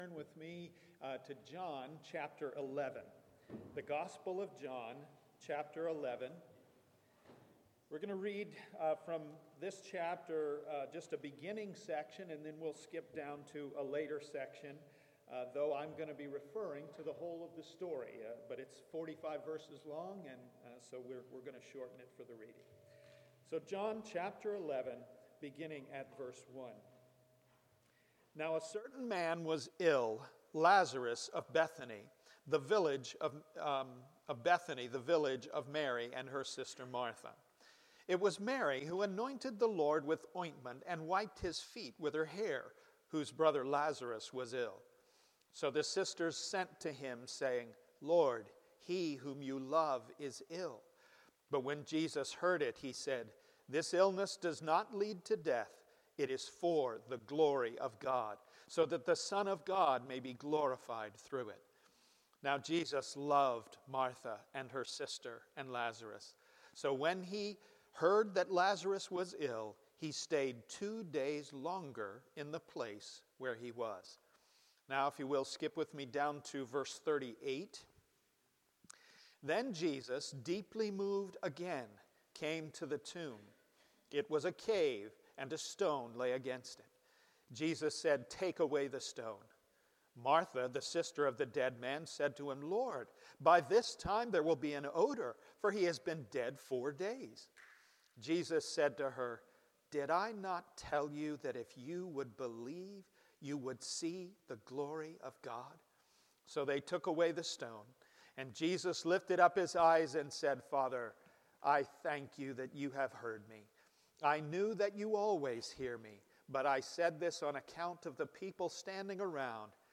Passage: John 11:1-45 Sermon